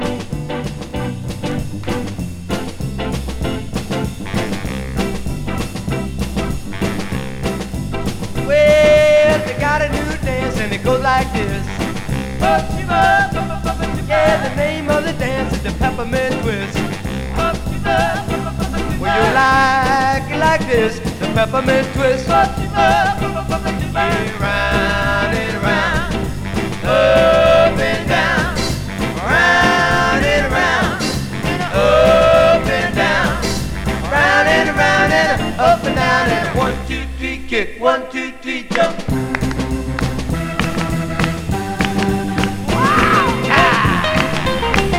Rock, Rock'nRoll, Twist, Pop　USA　12inchレコード　33rpm　Stereo